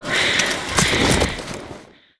挥毒掌击中zth070516.wav
通用动作/01人物/03武术动作类/挥毒掌击中zth070516.wav